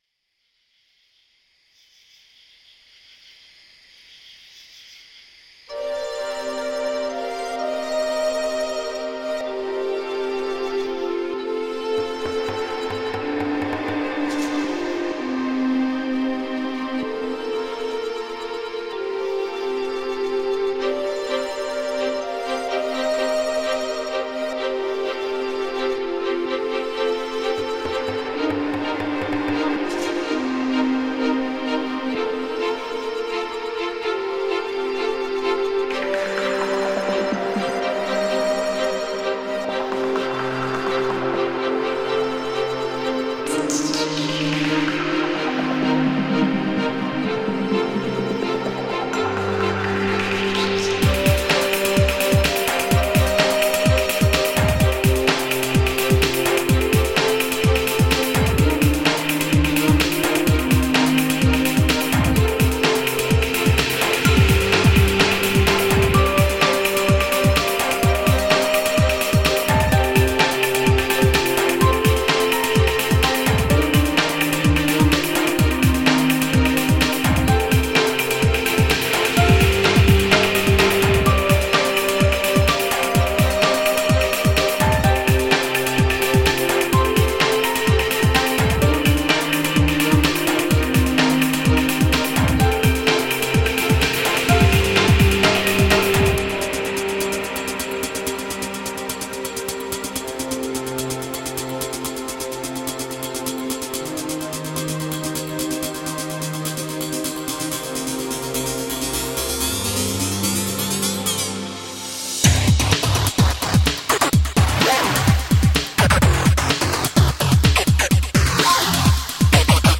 Straddling several electronica genres
Tagged as: Electro Rock, Ambient